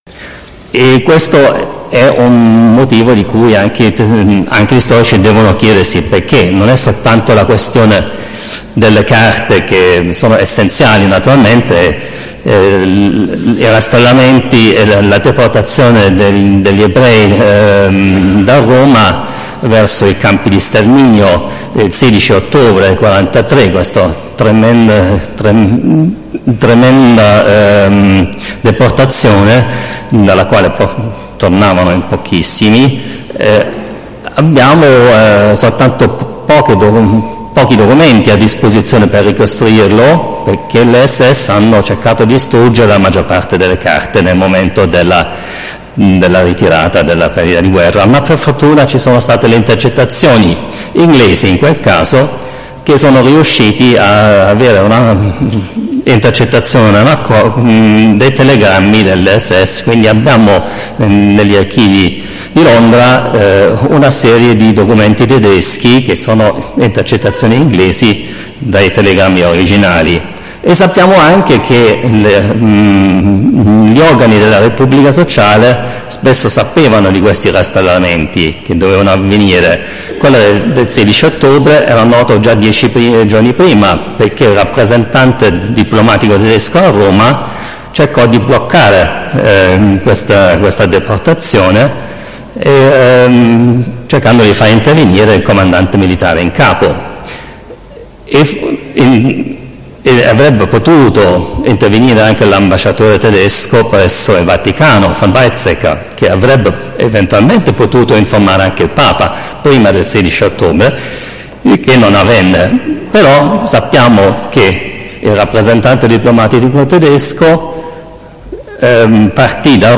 Nel 72° anniversario del rastrellamento del Quadraro, a Roma. Sala della Protomoteca del Campidoglio, Roma 15 aprile 2016.